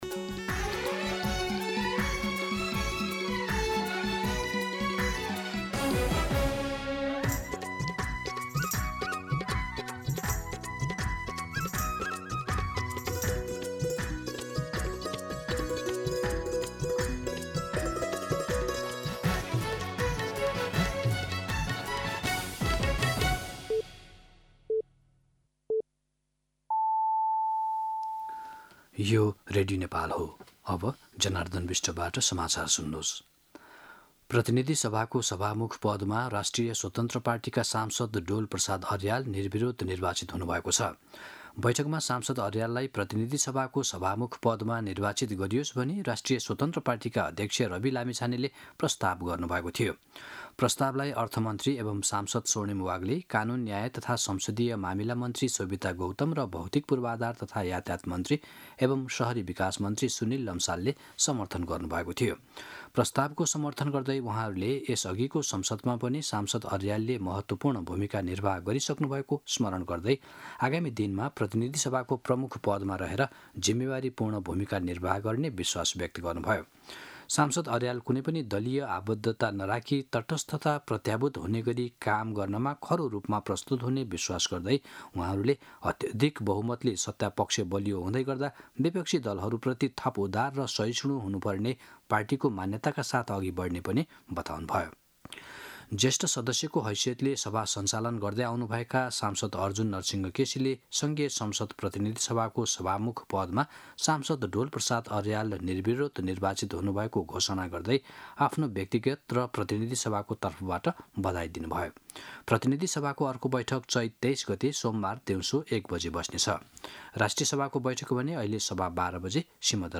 मध्यान्ह १२ बजेको नेपाली समाचार : २२ चैत , २०८२